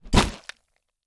HAMMER Hit Body (stereo).wav